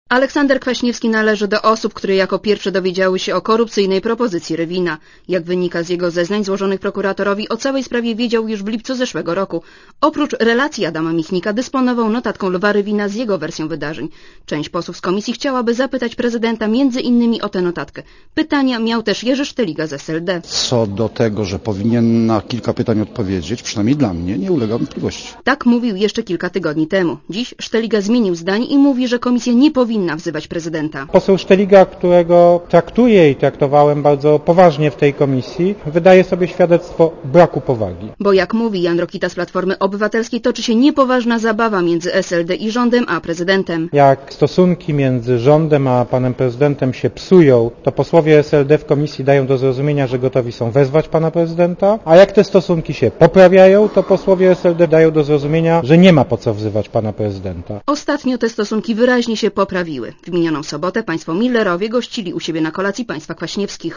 Komentarz audio (252Kb)